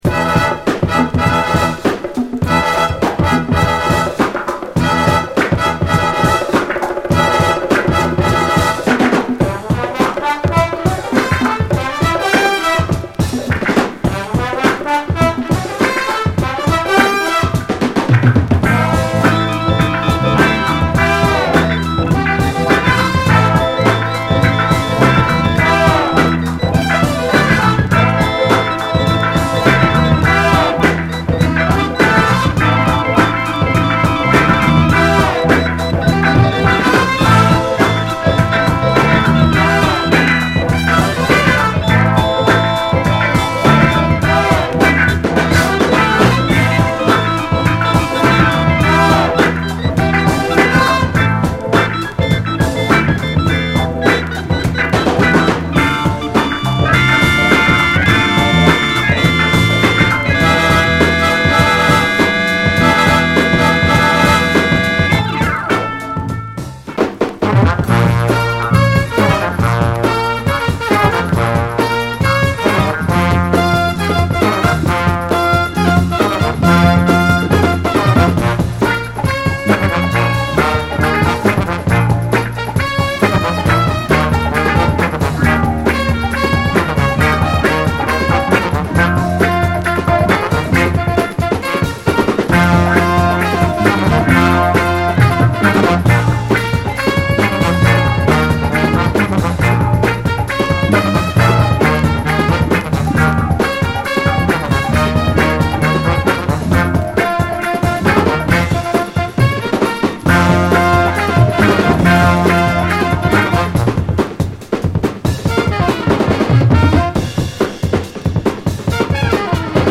怒涛のドラムブレイクも炸裂するB-Boy仕様の高速ファンク・カヴァーで超絶カッコいいです！
元々のプレスの都合上、薄いバックグラウンド・ノイズがあります。